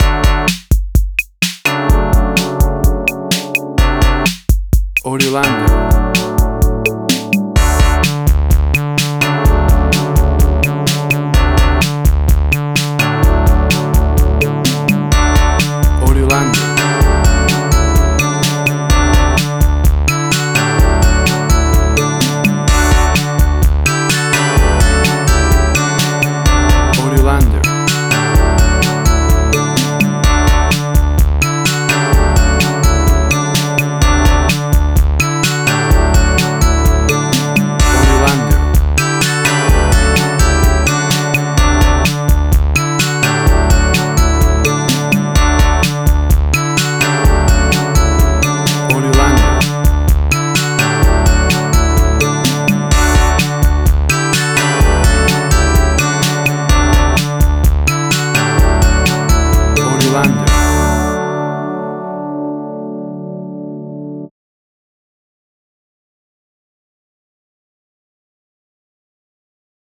A cool explosion of classic 80s synth music!
Tempo (BPM): 128